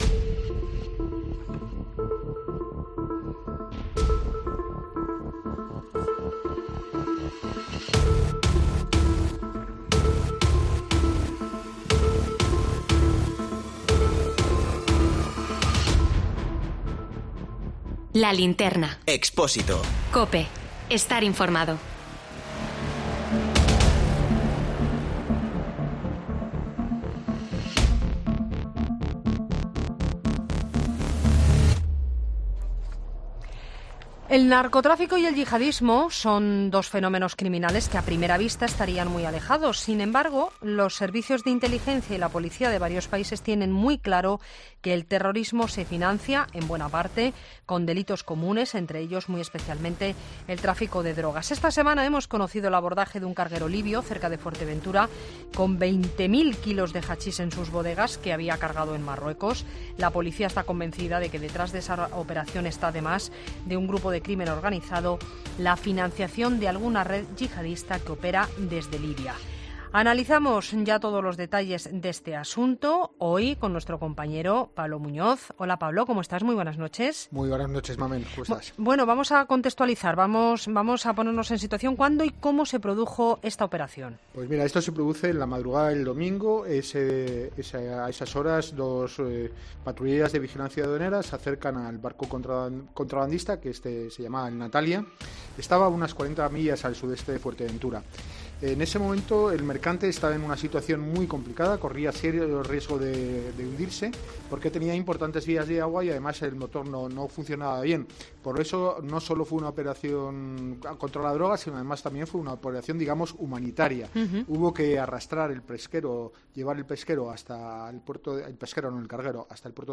Sucesos